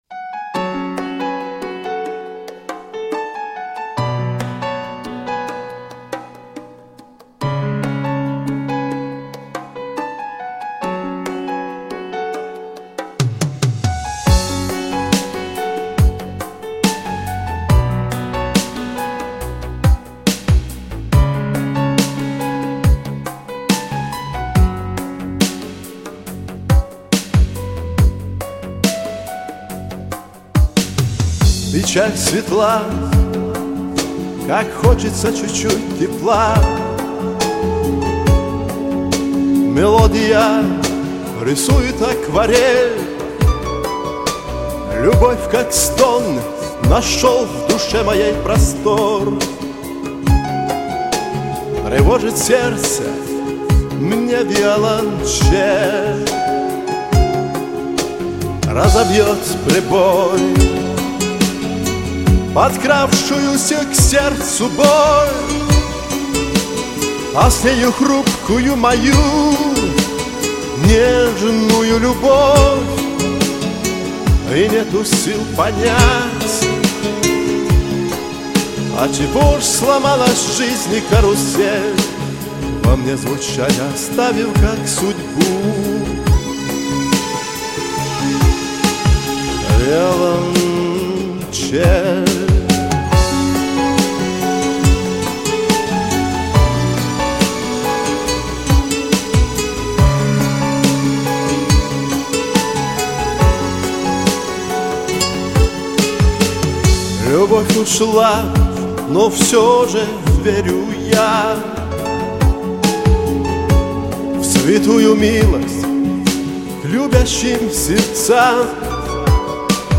Интонационно похоже есть легкий кавказский акцент.